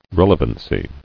[rel·e·van·cy]